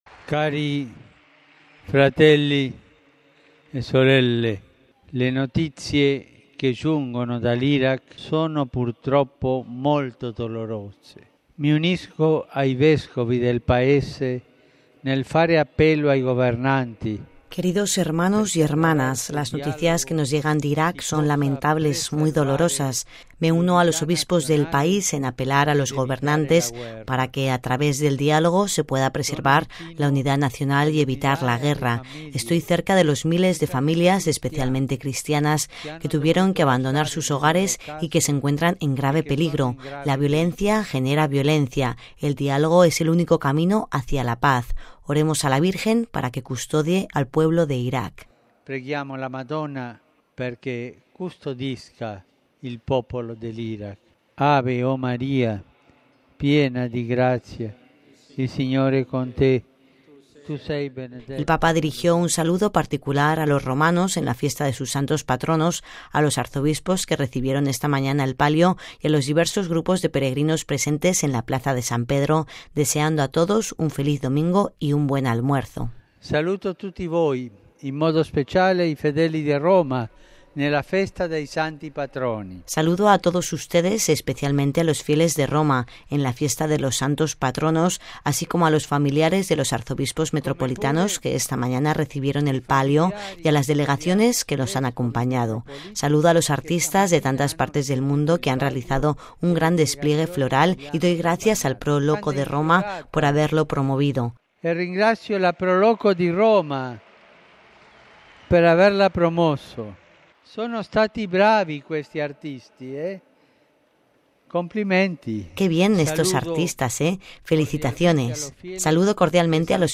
Palabras del Papa después del Ángelus: RealAudio